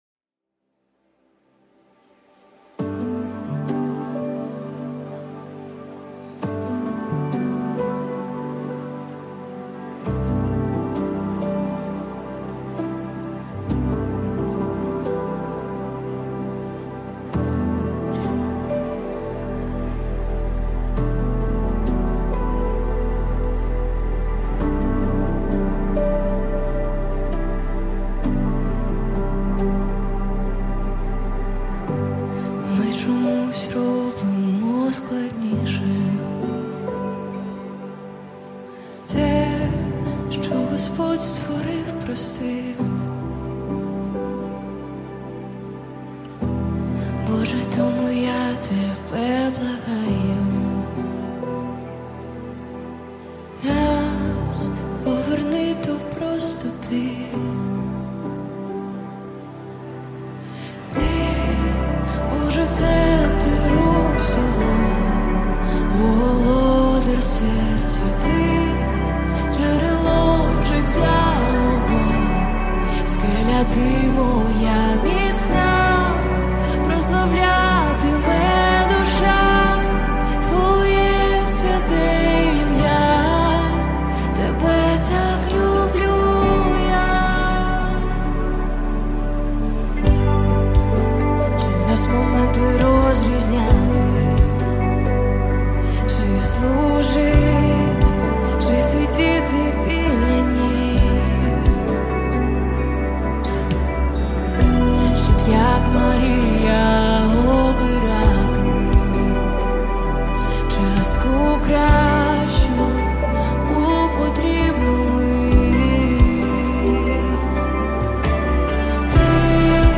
(Live)
BPM: 66